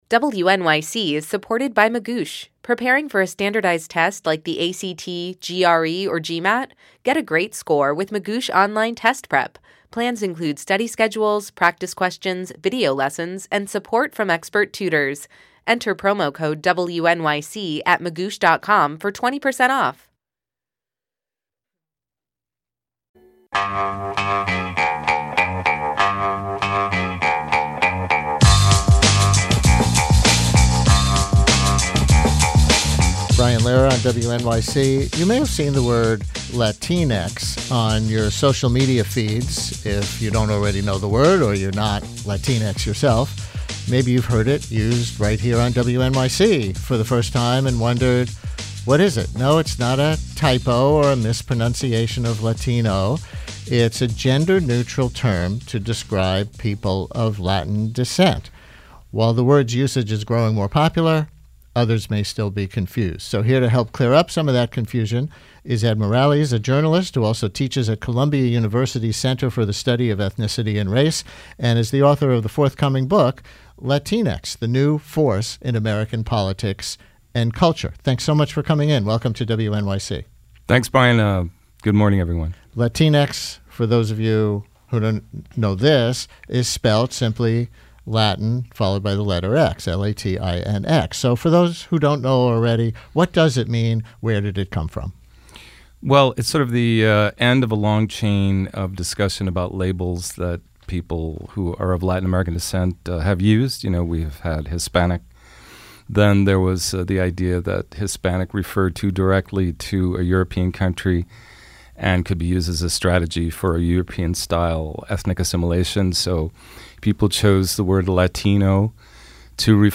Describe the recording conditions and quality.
As a result of all of this, I’ve been asked to appear on several radio programs to speak about Puerto Rico and the Maria aftermath, so I’ve compiled them here with links provided.